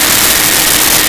Shop Vac Loop
Shop Vac Loop.wav